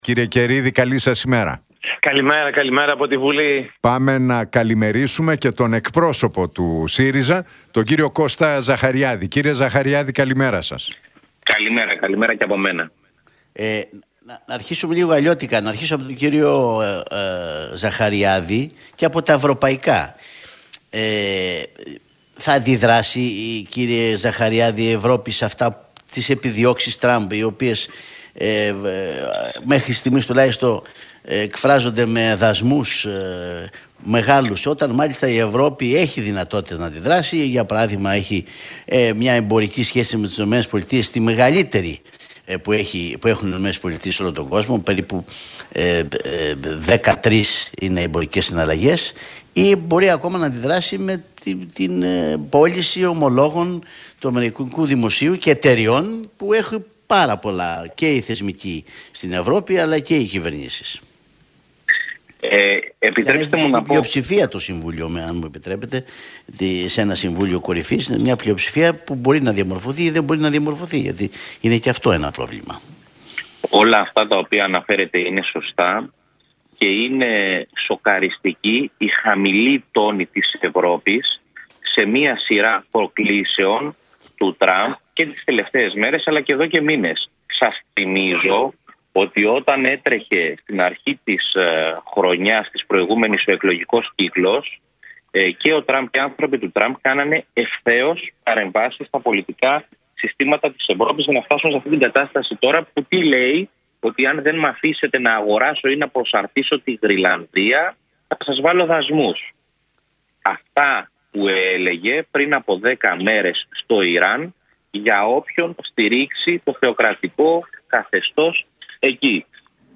Debate Καιρίδη - Ζαχαριάδη στον Realfm 97,8 για ελληνοτουρκικά, αγροτικό, Καρυστιανού και Τραμπ